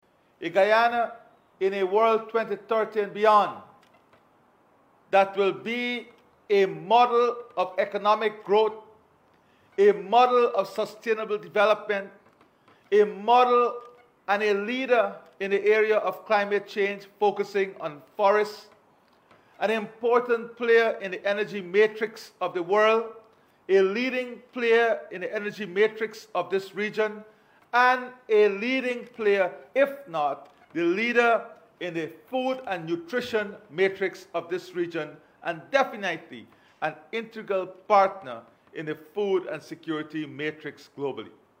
In a recent address to the nation commemorating the anniversary of the March 2020 elections, President Irfaan Ali reiterated the People’s Progressive Party (PPP) government’s unwavering dedication to advancing Guyana’s prosperity and resilience.